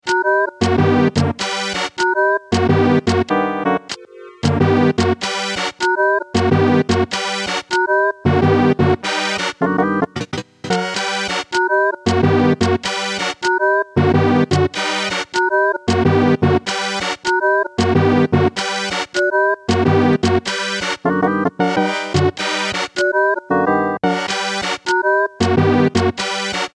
2 LFOtrigger active
It also happens that the shorting sometimes alters the timbre.